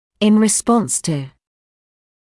[ɪn rɪ’spɔns tuː][ин ри’спонс туː]в ответ на; в качестве реакции на